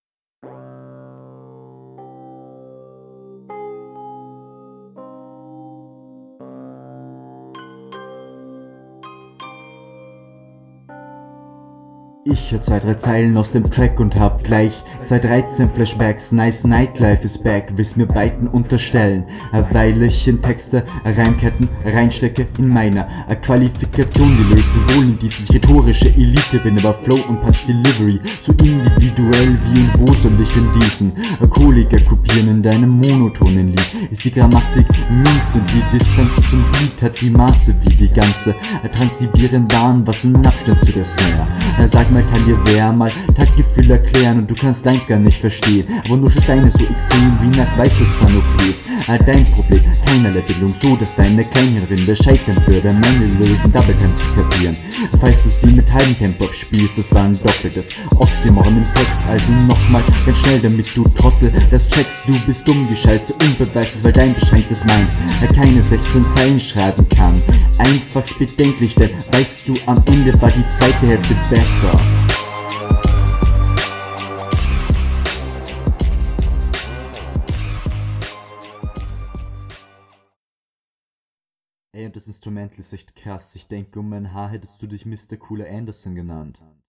Flow war bis auf kleinere Fehler größtenteils in Ordnung. Deine Stimme klingt auch zu lasch.